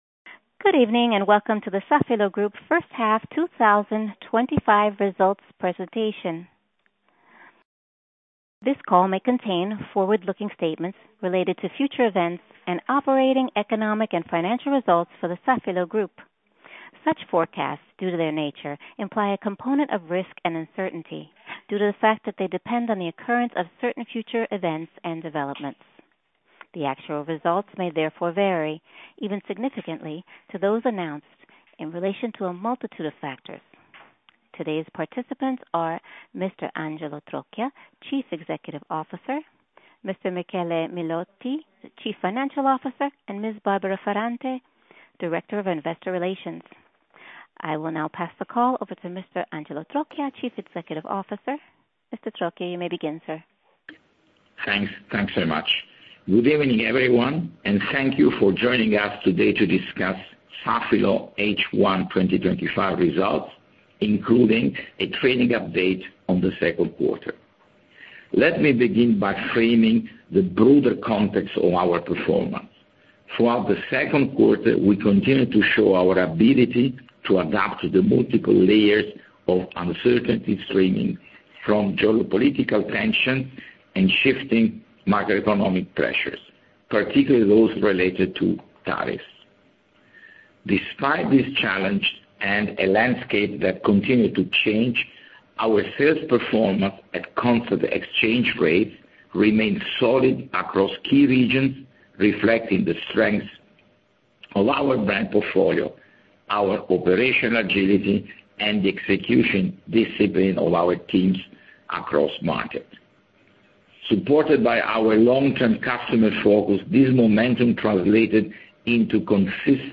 Safilo_H1_2025_conference_call.mp3